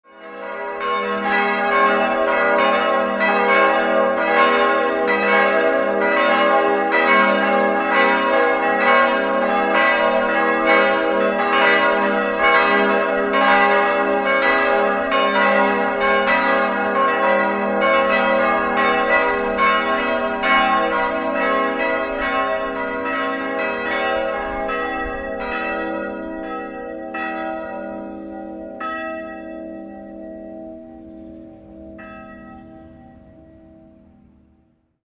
Idealquartett: gis'-h'-cis''-e''
Sie wiegen 541, 325, 226 und 156 kg.
bell
Die Geläute der Christuskirche und der benachbarten Hofkirche sind aufeinander abgestimmt und bilden gemeinsam ein beeindruckendes 10-stimmiges Ensemble.